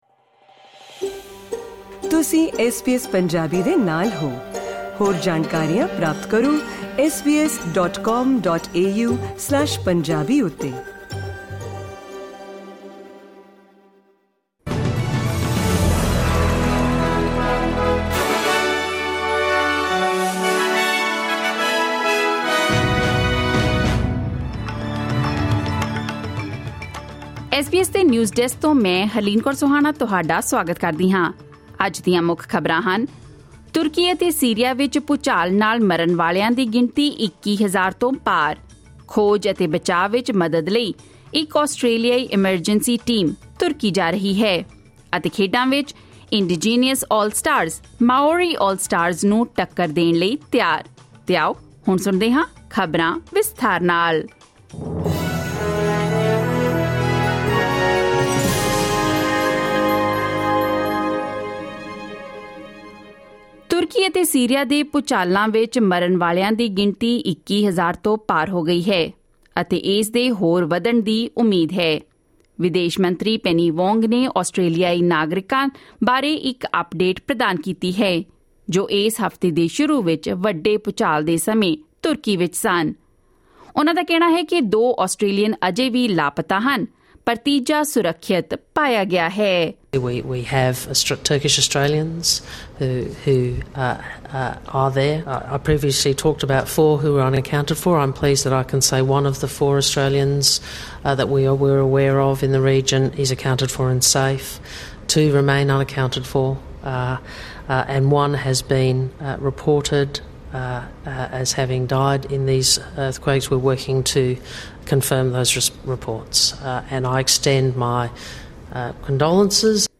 SBS Punjabi Australia News: Friday 10 February 2023